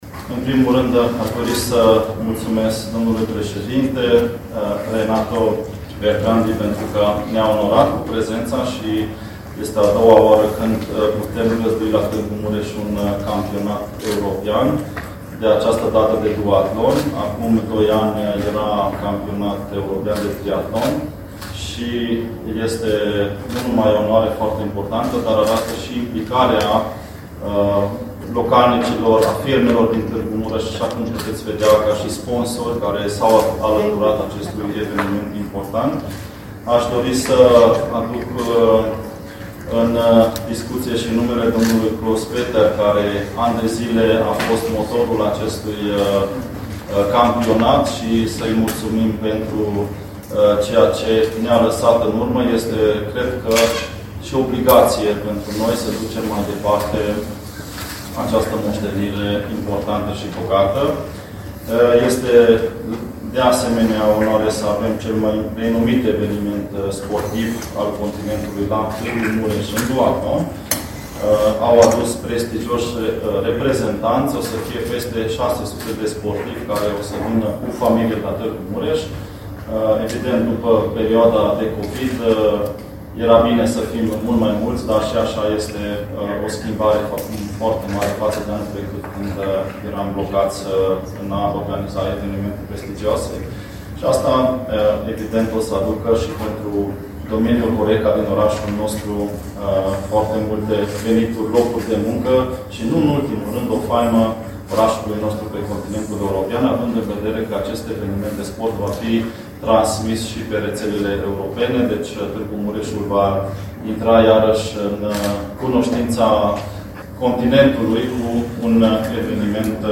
La conferința de presă organizată joi, 1 iulie, la Hotel Grand din Tg.-Mureș, cu prilejul Campionatelor Europene de duathlon